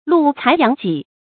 露才揚己 注音： ㄌㄨˋ ㄘㄞˊ ㄧㄤˊ ㄐㄧˇ 讀音讀法： 意思解釋： 顯露自己的才能。